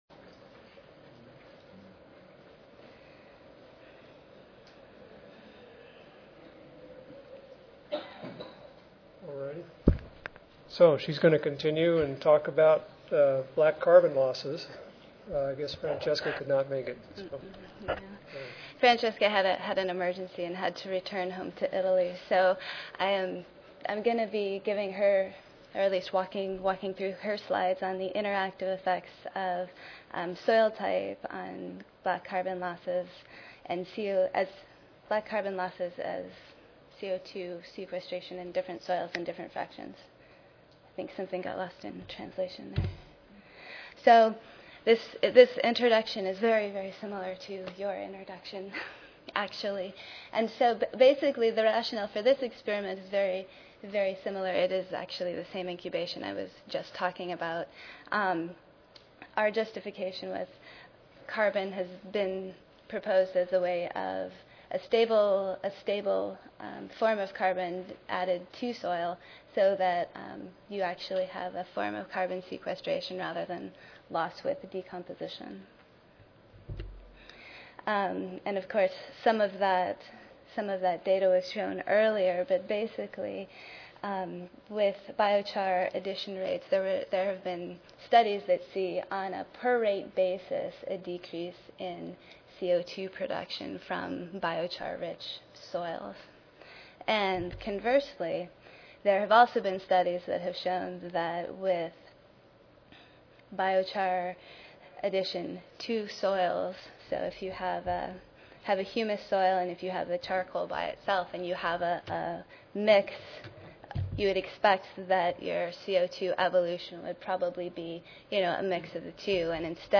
Northwest A & F University Recorded Presentation Audio File